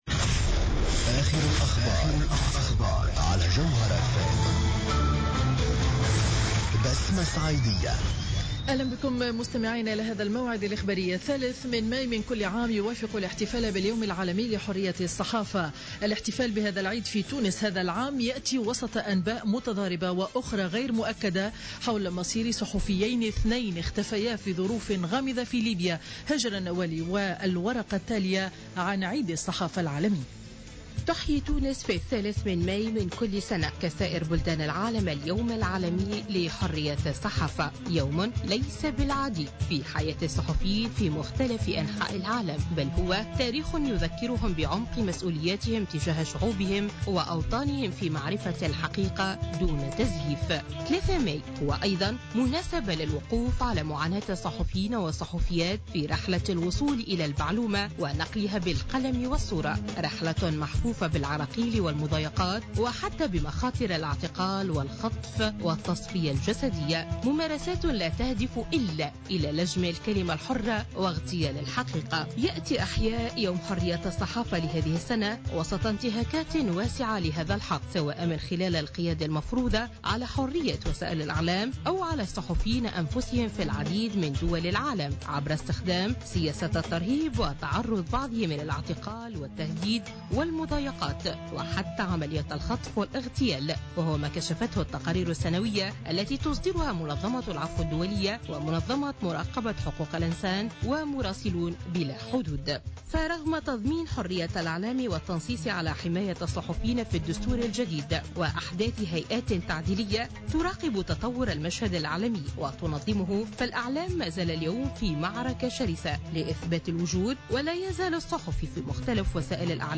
نشرة أخبار منتصف ليوم الأحد 03 ماي 2015